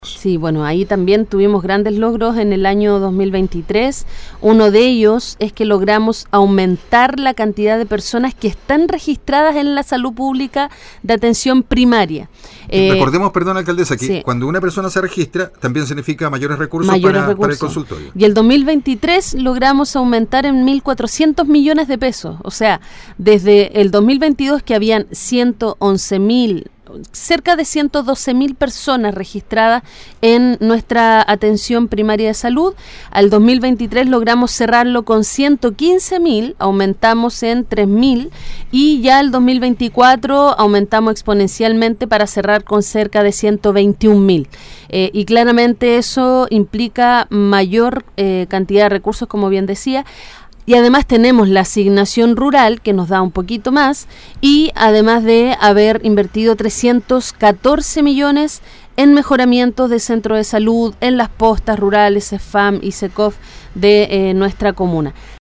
Durante una entrevista realizada en “La Mañana de Todos” de Radio Ignacio Serrano, la alcaldesa Olavarría respondió a las dudas  más importantes para la comuna de Melipilla